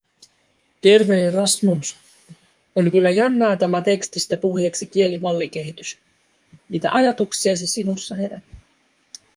test_tts_finetuned_5k_steps.wav